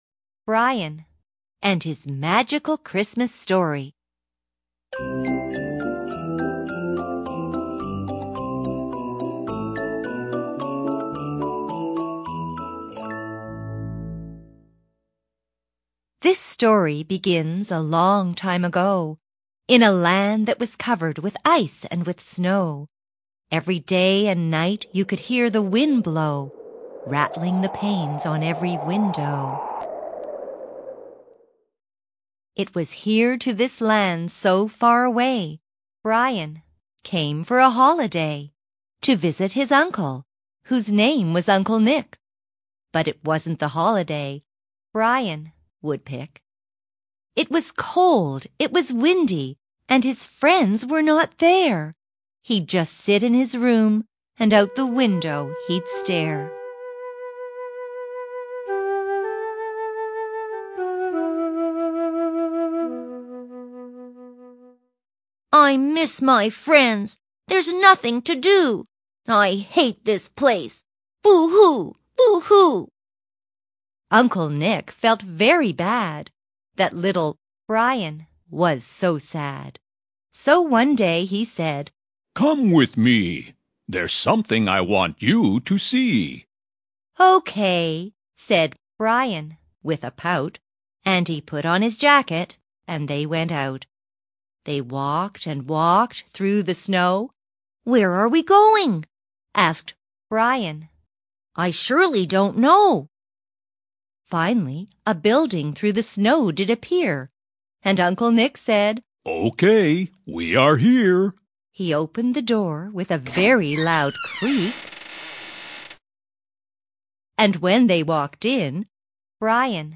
Format: Audio Story CD